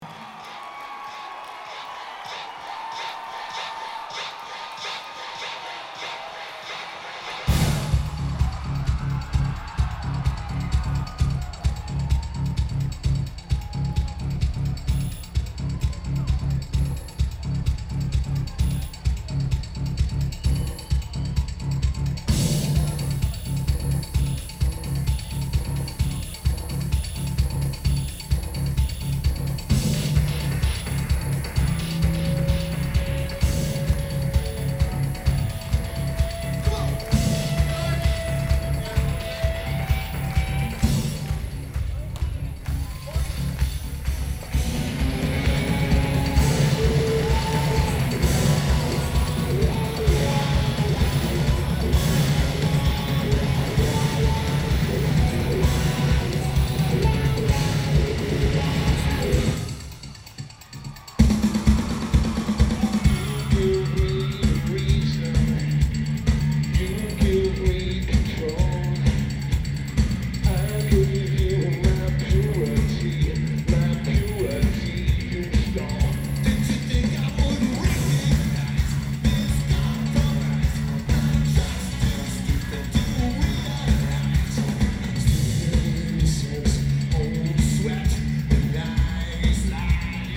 DTE Energy Music Theatre
Drums
Bass
Vocals/Guitar/Keyboards
Lineage: Audio - AUD (At 943's + SPSB-11 + R09HR)